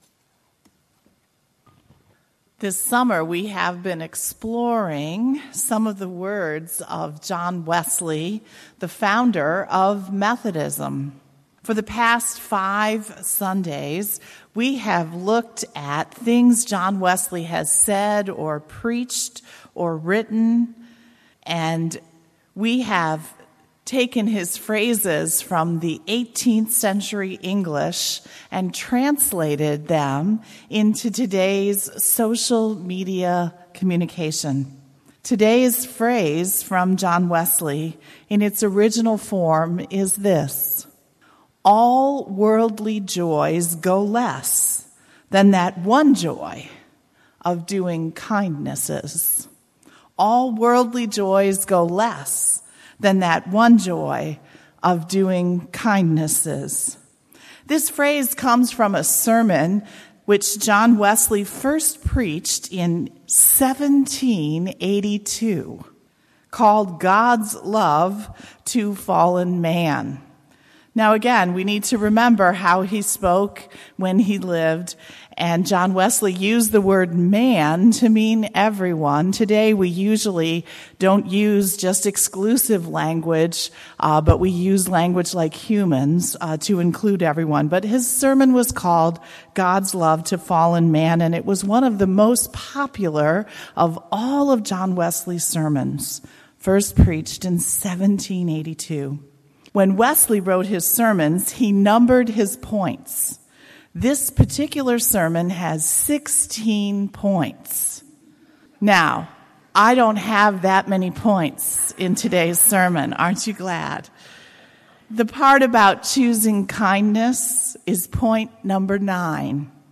2019-08-15 Sermon, “Choose Kind!”